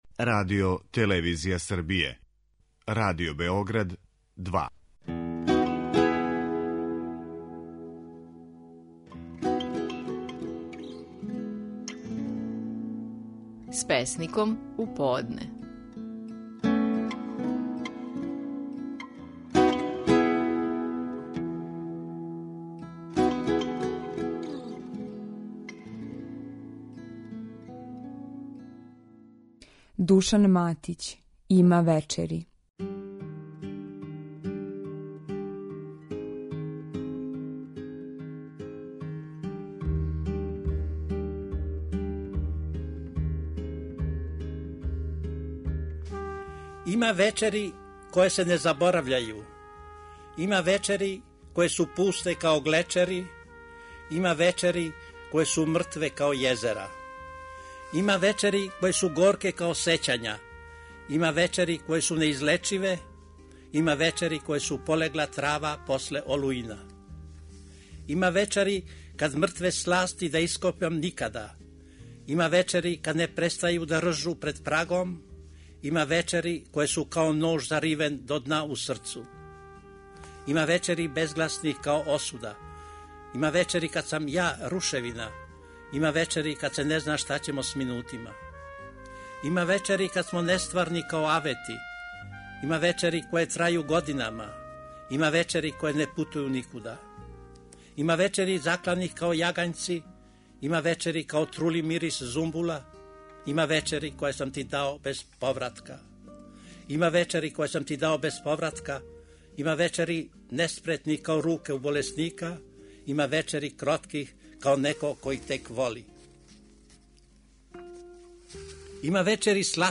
Наши најпознатији песници говоре своје стихове.
Данас је то Душан Матић, а песма је "Има вечери".